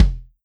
S_kick_1.wav